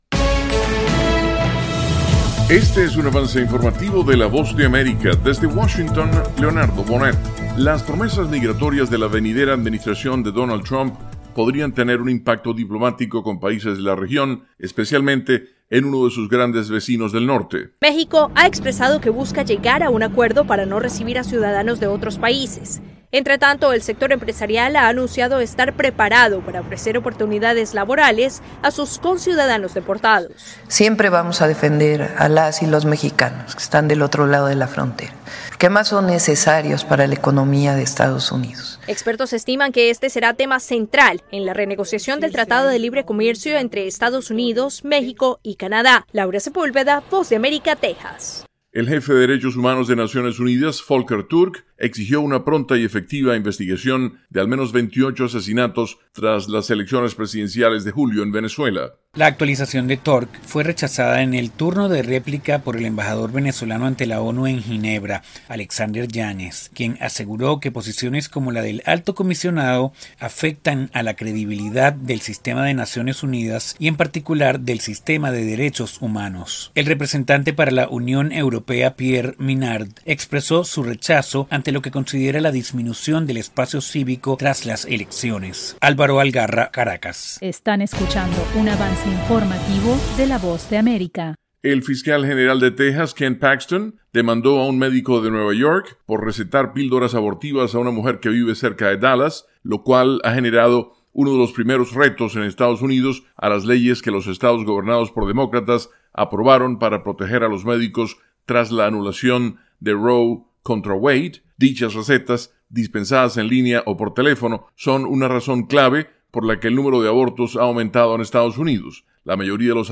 Avance Informativo